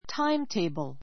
táimteibl